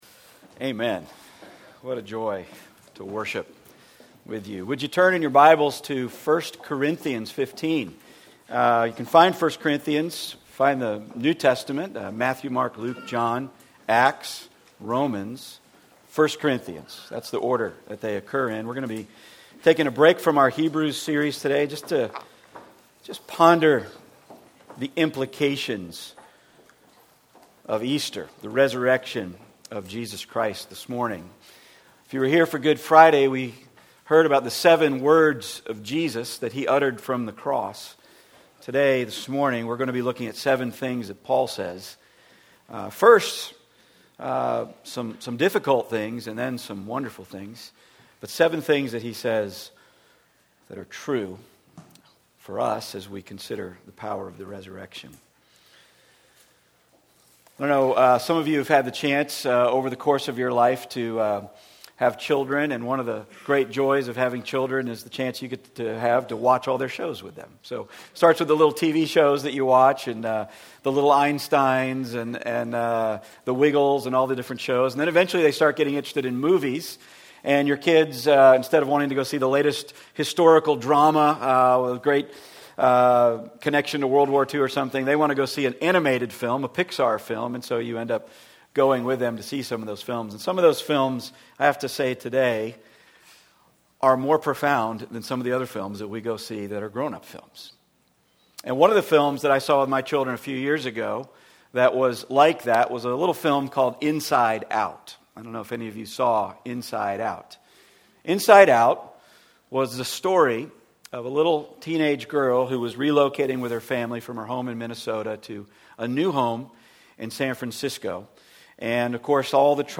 Passage: I Corinthians 15:1-22 Service Type: Weekly Sunday